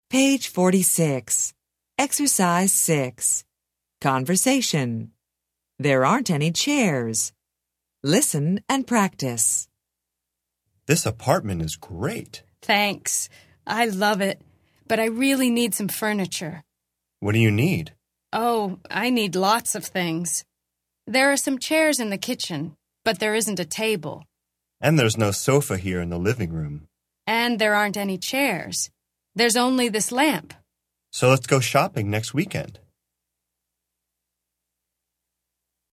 Interchange Third Edition Intro Unit 7 Ex 6 Conversation Track 20 Students Book Student Arcade Self Study Audio
interchange3-intro-unit7-ex6-conversation-track20-students-book-student-arcade-self-study-audio.mp3